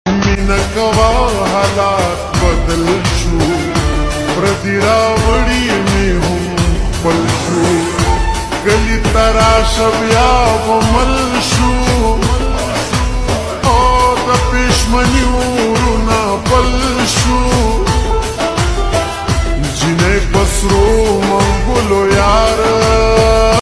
pashtosong